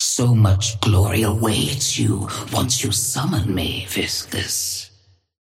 Sapphire Flame voice line - So much glory awaits you once you summon me, Viscous.
Patron_female_ally_viscous_start_05.mp3